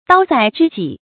叨在知己 tāo zài zhī jǐ
叨在知己发音